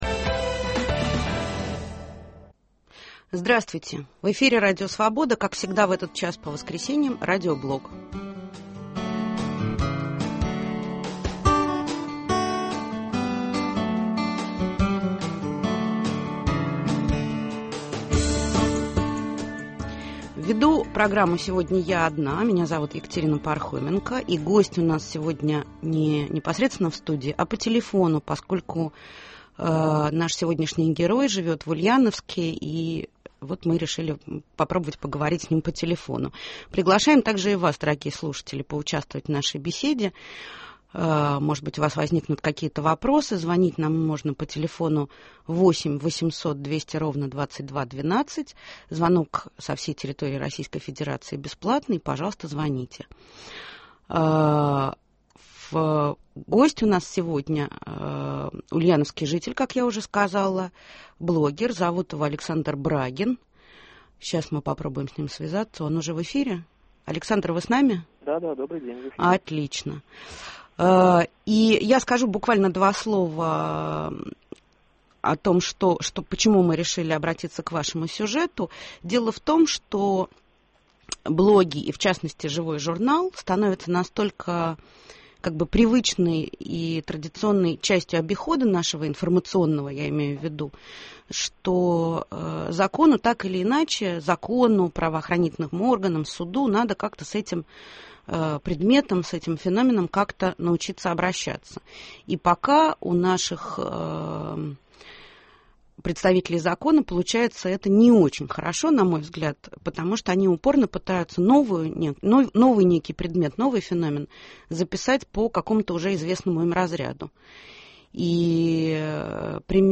гость воскресного Радиоблога